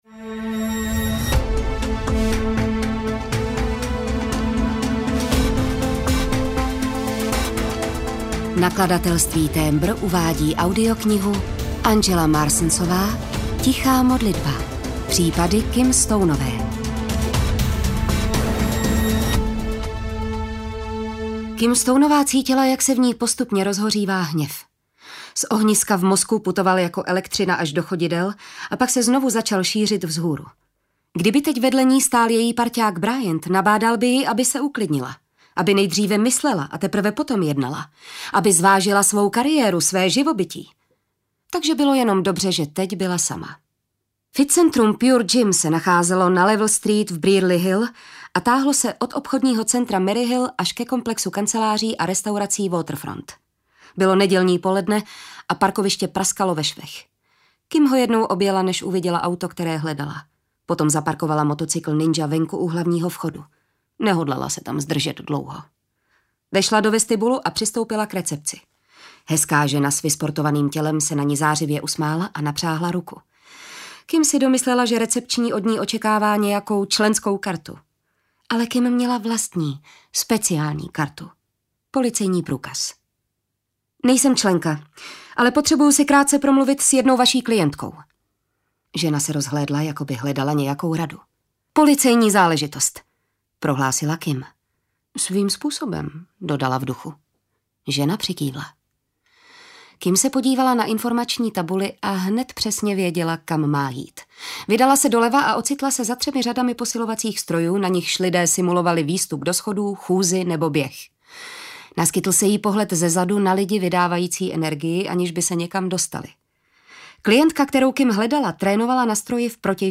Tichá modlitba audiokniha
Ukázka z knihy